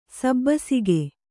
♪ sabbasige